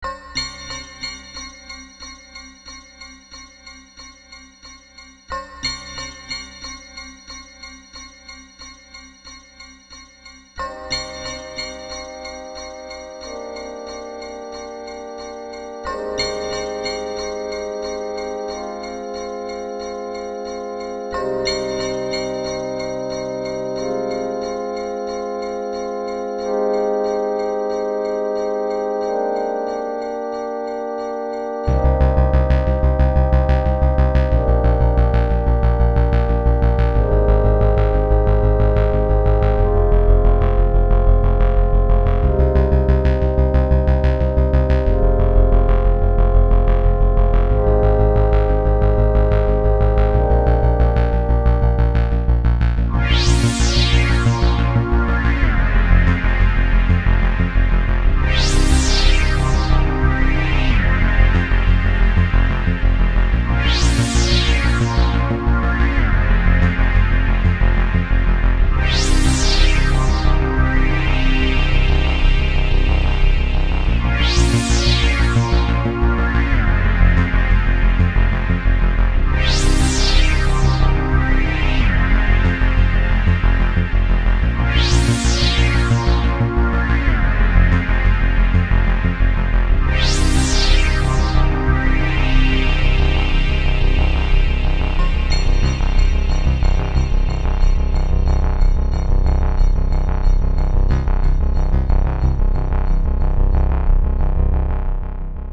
Scifi theme with a tension
• Music is loop-able, but also has an ending